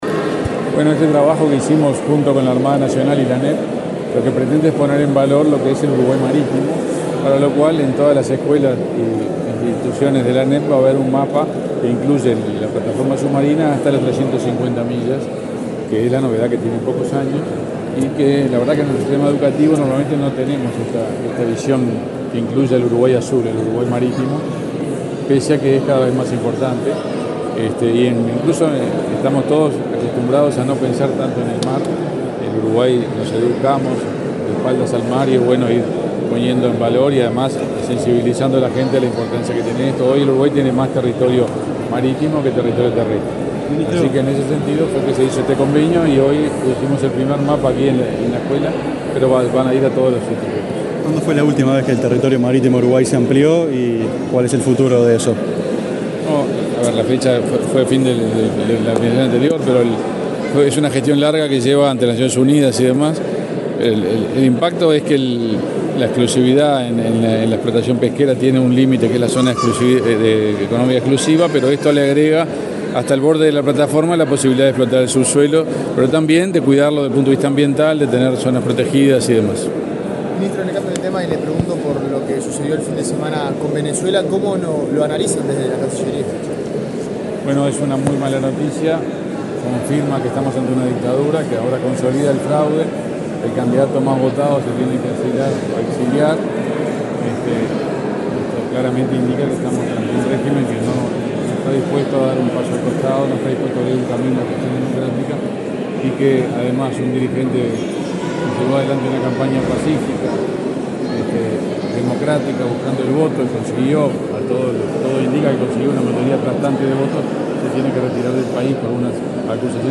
Declaraciones del canciller Omar Paganini a la prensa
Declaraciones del canciller Omar Paganini a la prensa 09/09/2024 Compartir Facebook X Copiar enlace WhatsApp LinkedIn Este lunes 9 en la sede del Ministerio de Relaciones Exteriores, el canciller Omar Paganini dialogó con la prensa, luego de participar en la presentación de una lámina del Uruguay con sus jurisdicciones marítimas, realizada por el Servicio de Oceanografía, Hidrografía y Meteorología de la Armada Nacional.